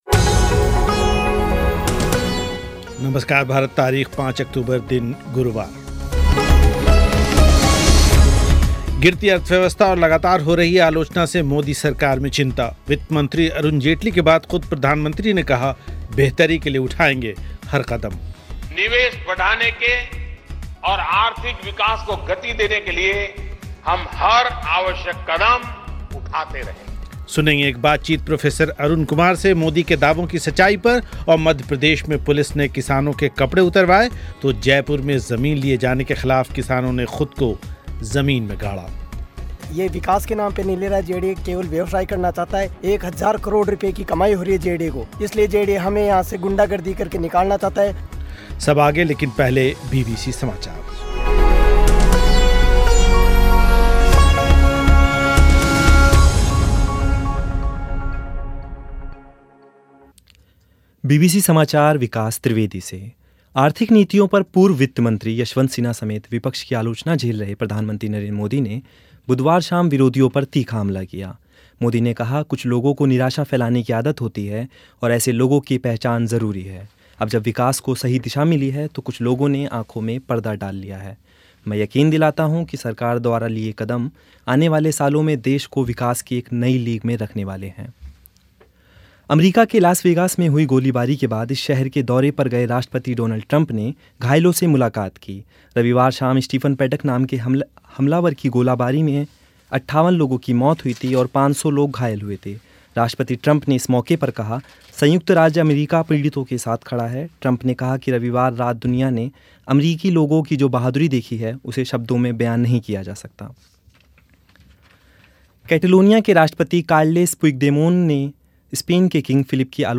बातचीत